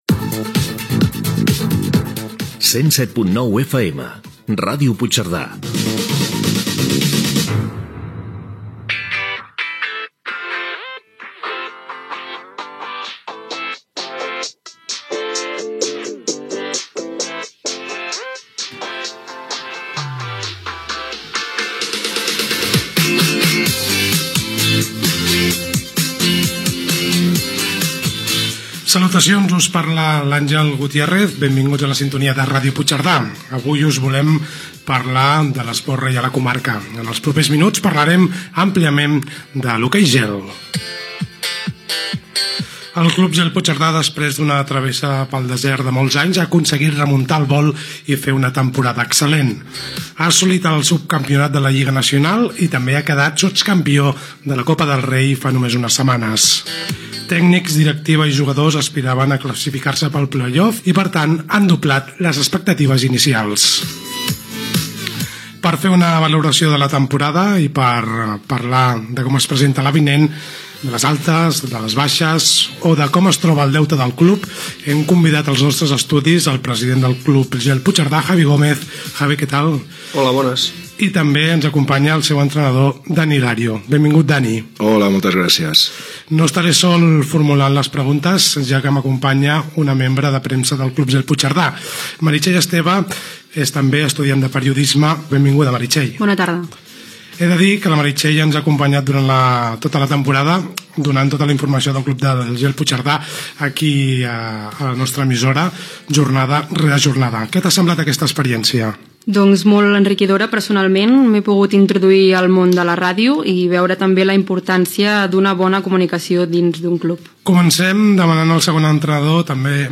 L'entrevista (Ràdio Puigcerdà)
Informatiu